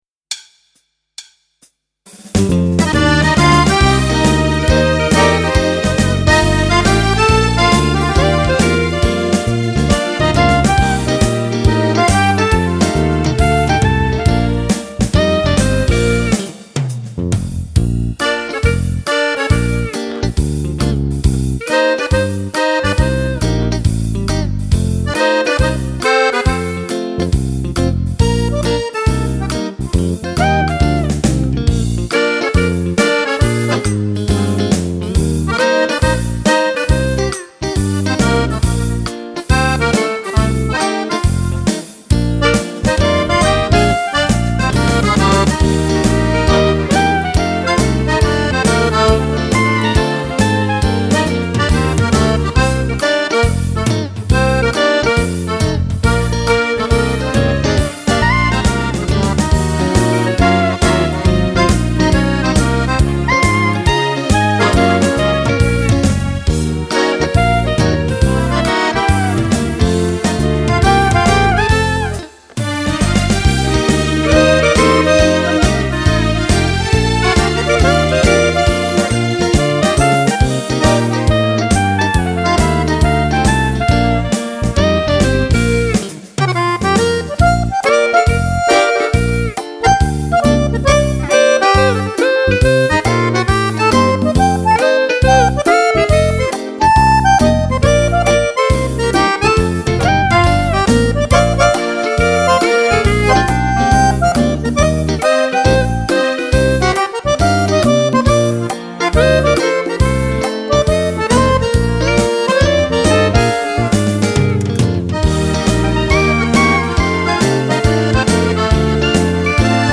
Swing fox
Fisar. / Sax